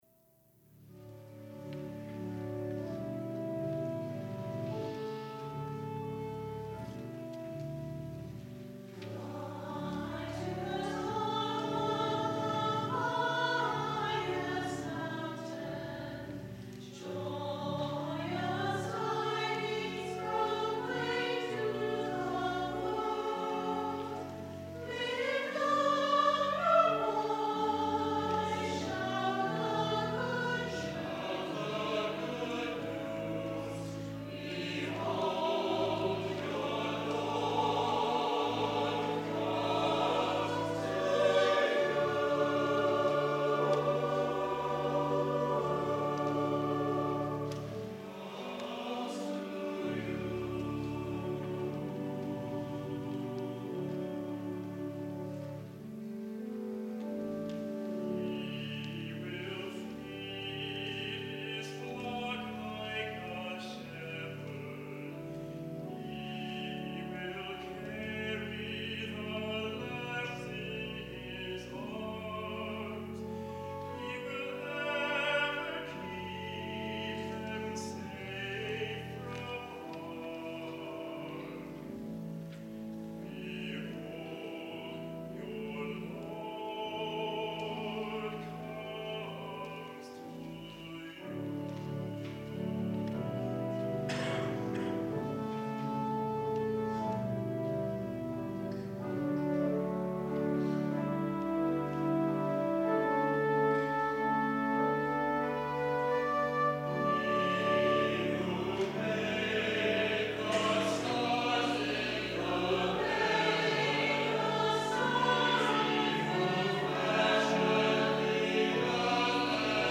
Chancel Choir
organ